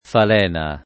falena [ fal $ na ] s. f.